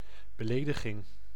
Ääntäminen
Synonyymit vexation Ääntäminen France: IPA: [a.fʁɔ̃] Haettu sana löytyi näillä lähdekielillä: ranska Käännös Ääninäyte Substantiivit 1. smaad {m} 2. belediging {f} 3. affront 4. krenking Suku: m .